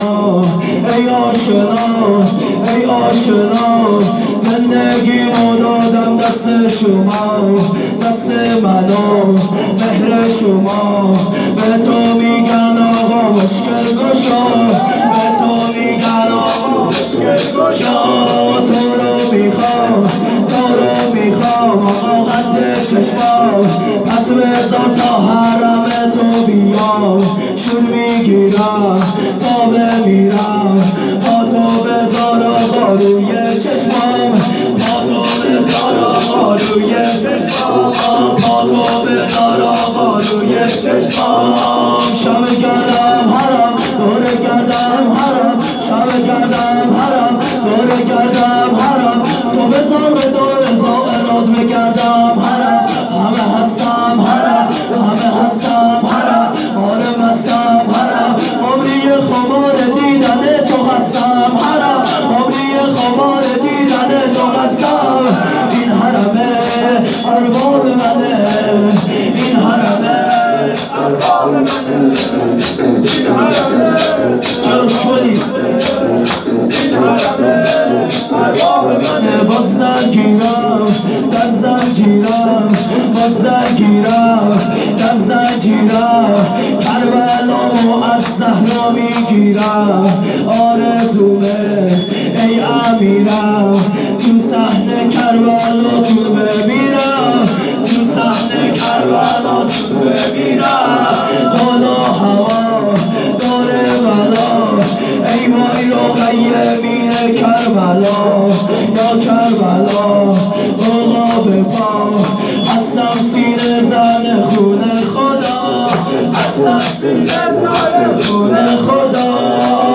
گزارش صوتی جلسه
[شور،ای اشنا زندگیم و دادم]
[مراسم هفتگی97/2/27]